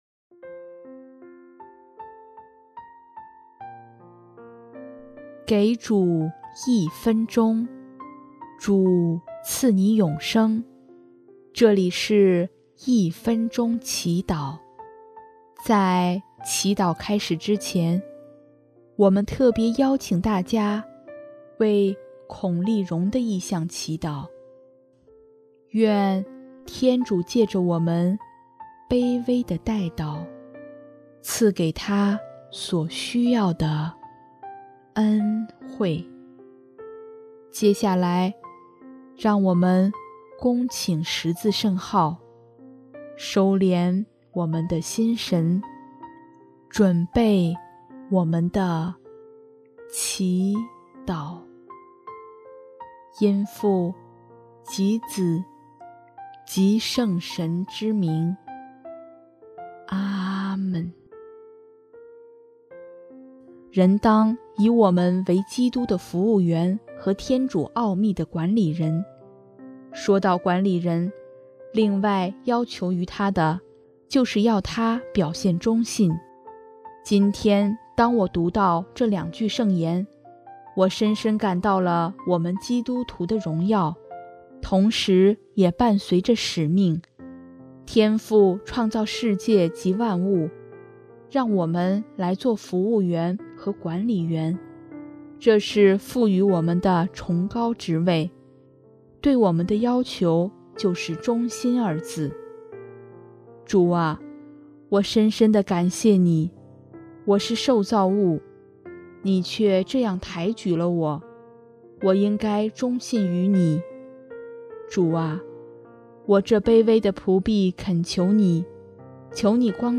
【一分钟祈祷】|9月6日 我们当做忠信的仆人